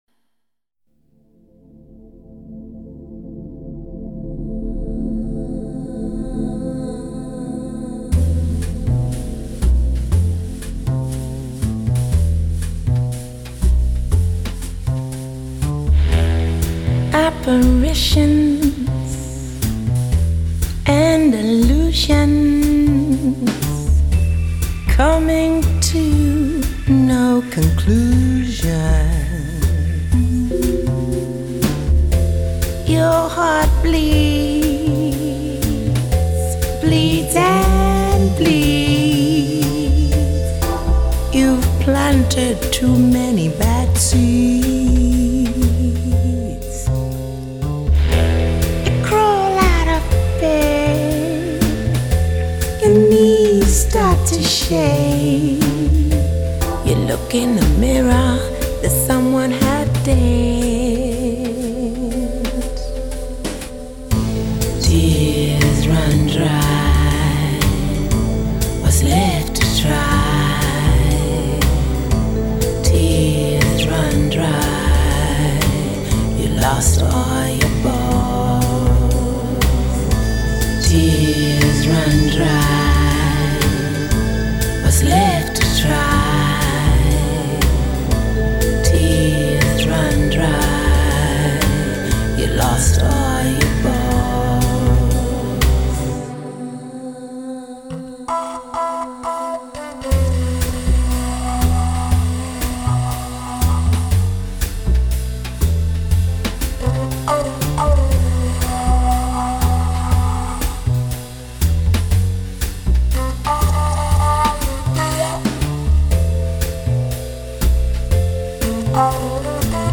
Что бас, что фоно, что барабаны - явно неживые.